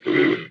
animalia_reindeer_death.ogg